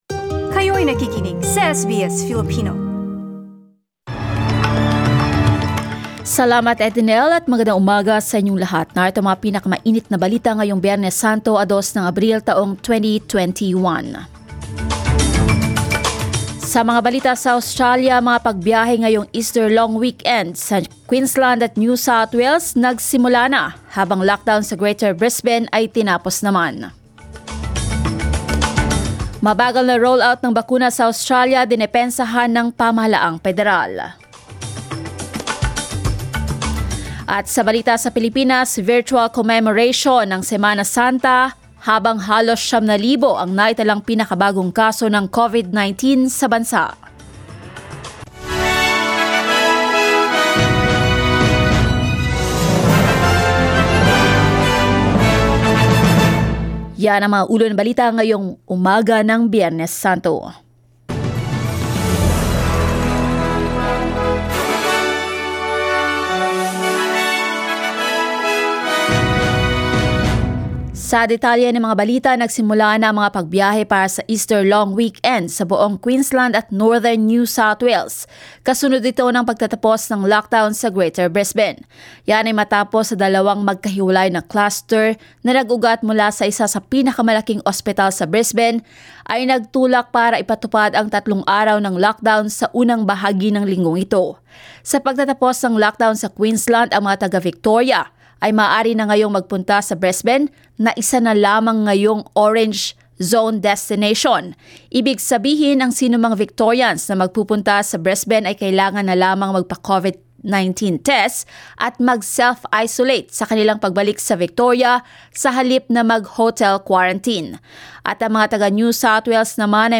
SBS News in Filipino, Friday 2 April